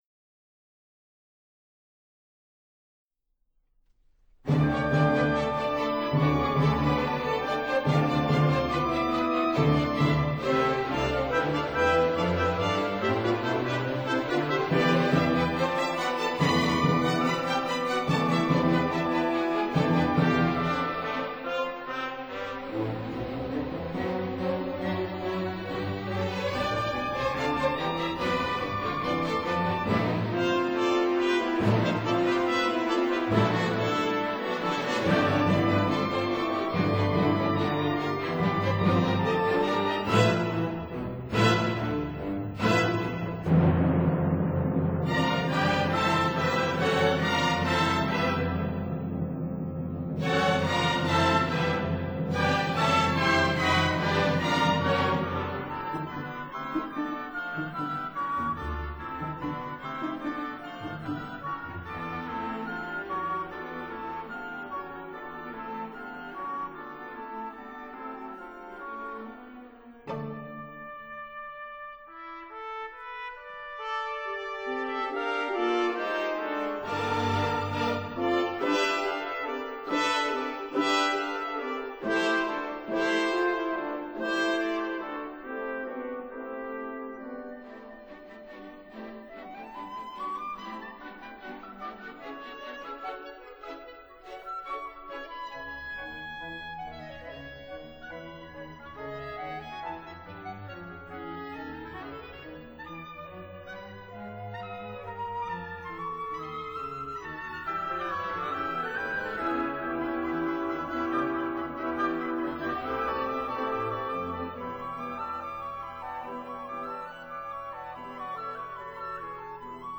其作品常呈現新古典主義風格，善於用復調手法發展短小的動機，並有突出的北歐色彩。